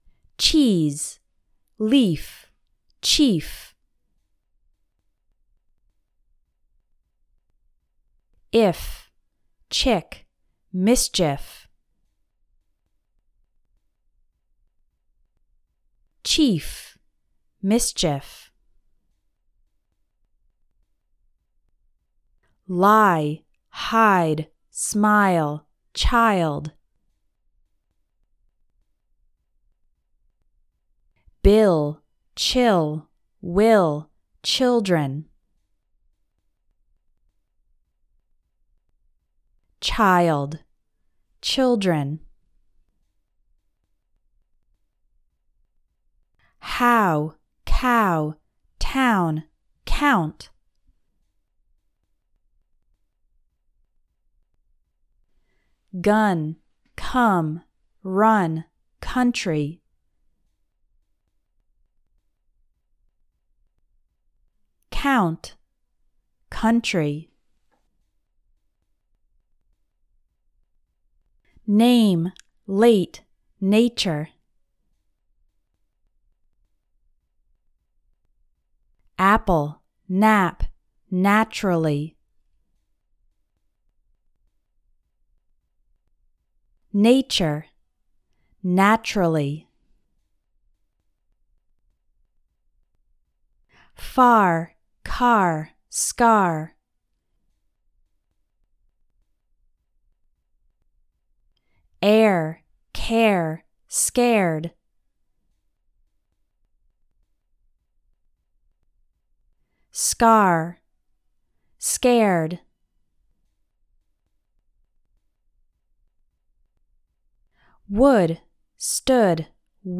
Words that seem similar but have different pronunciations:
I hope you’ve enjoyed today’s pronunciation practice!